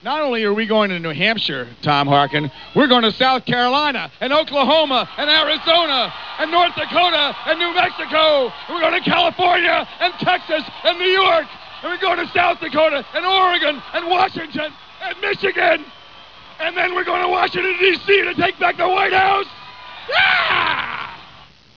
Either way, a lot of people’s heads were turned upon hearing him give some kind of wild “Yeah!” at the end of a strongly delivered sentence.
WAV file of the entire sentence (250 KB, 8-bit sound at 11KHz), or for those who want a clip for your sound archive or just a really weird ‘beep’ sound for your computer,